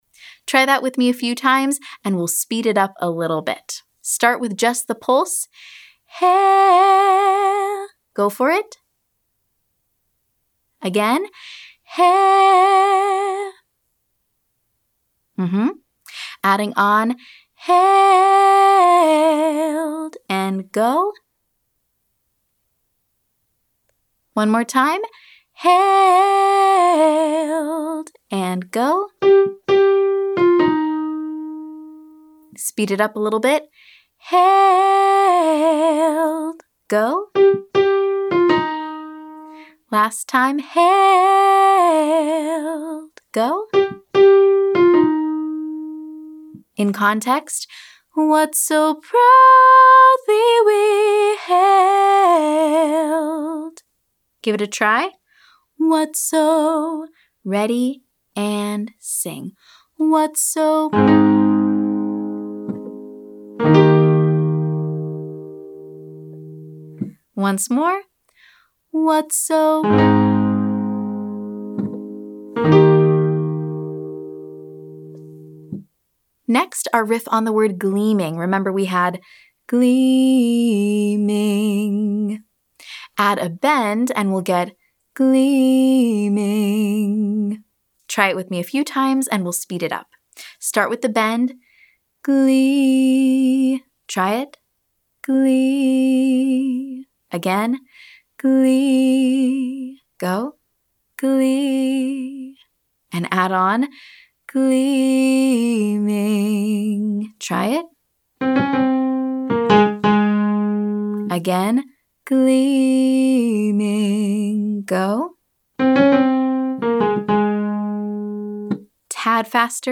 Take that same exercise, but this time start with a bend! Same as before, we’ll listen and repeat with each pattern: ascending, descending, and both.